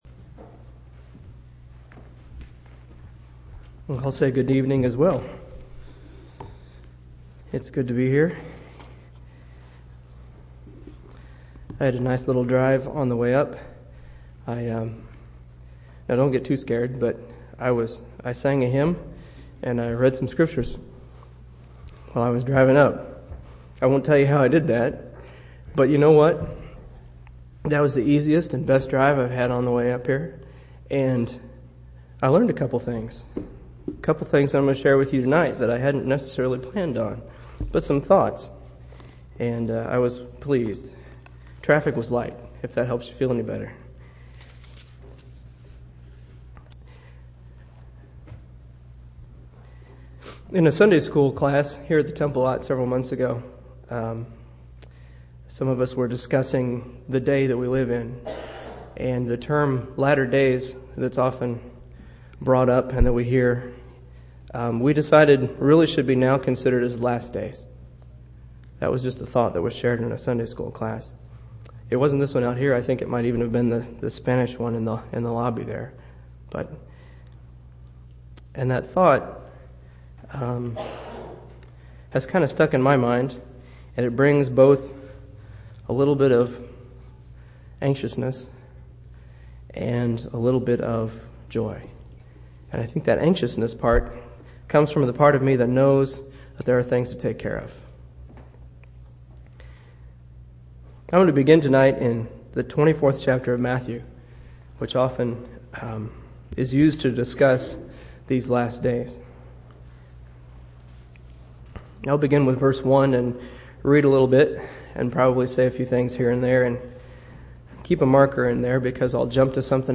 3/9/2003 Location: Temple Lot Local Event